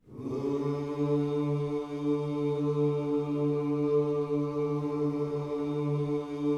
WHOO D 3E.wav